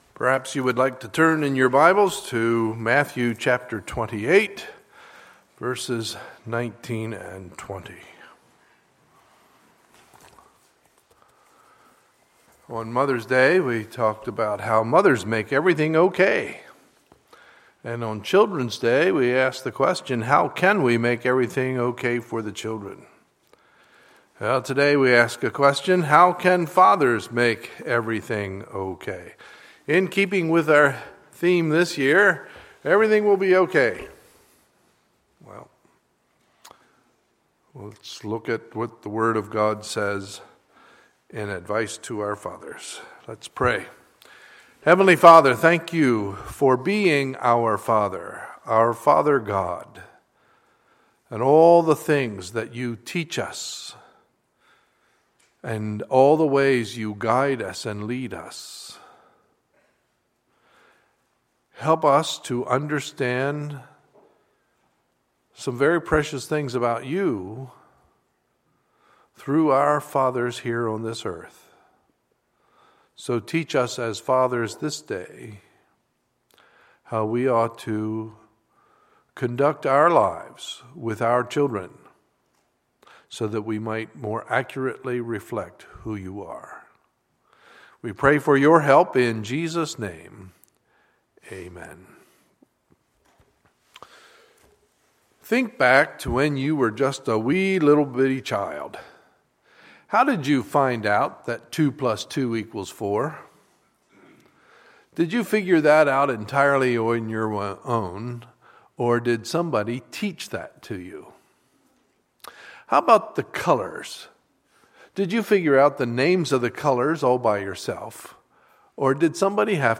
Sunday, June 21, 2015 – Sunday Morning Service